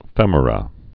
(fĕmər-ə)